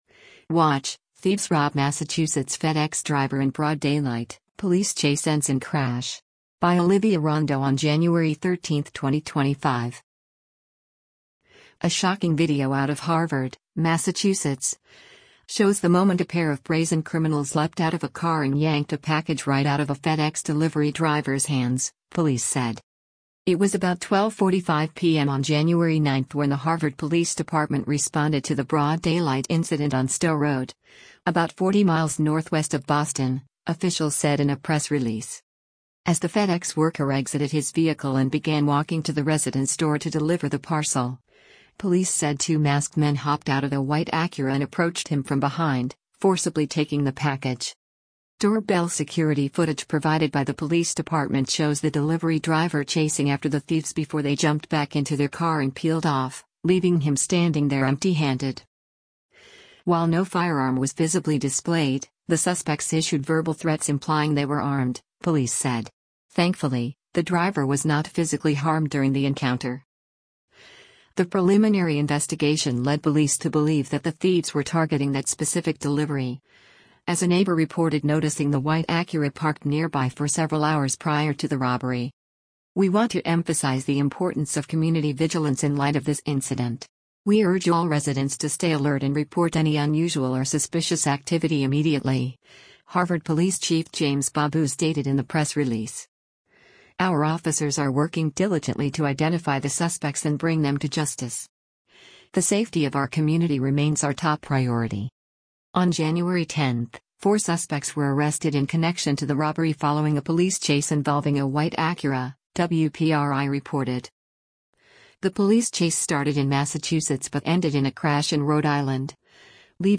Doorbell security footage provided by the police department shows the delivery driver chasing after the thieves before they jumped back into their car and peeled off, leaving him standing there empty-handed.